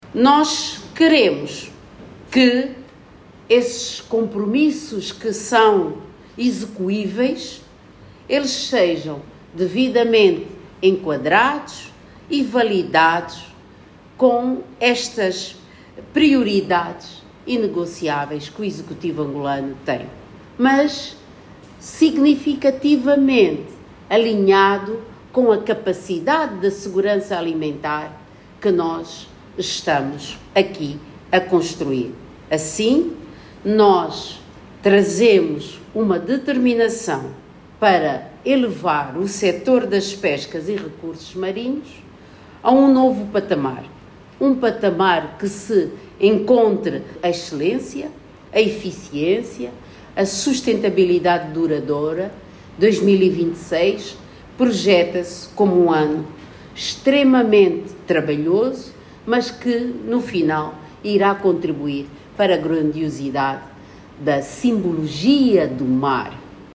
Para tal, o departamento ministerial iniciou, esta sexta-feira, durante o Conselho Consultivo, a definição de uma nova arquitectura de gestão das pescas e recursos marinhos.
De acordo com a ministra Carmen do Sacramento Neto, esta pretensão tem em conta as necessidades actuais do país e a sustentabilidade futura do sector.